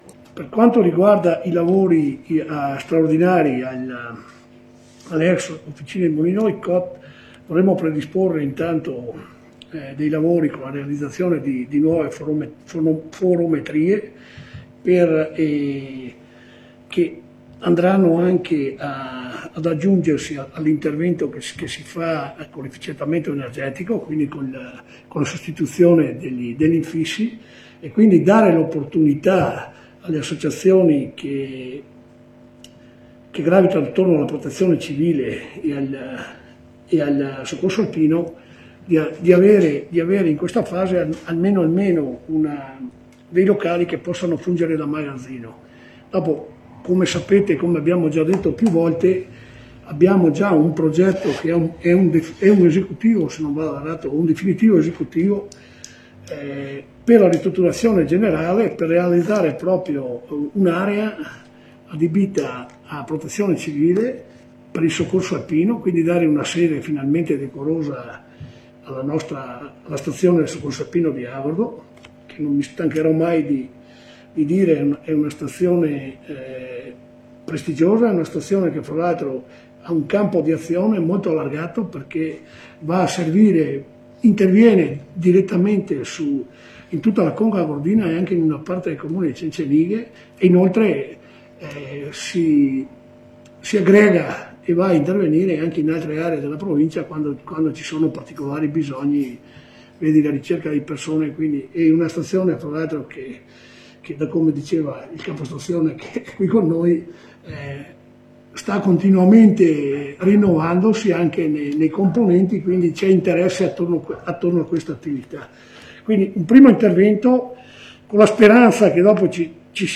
AGORDO In consiglio comunale ad Agordo il sindaco Roberto Chissalè ha parlato anche dei progetti per le ex officine dei congegnatori in Via Molin dei Cot